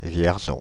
Vierzon (French pronunciation: [vjɛʁzɔ̃]
Fr-Paris--Vierzon.ogg.mp3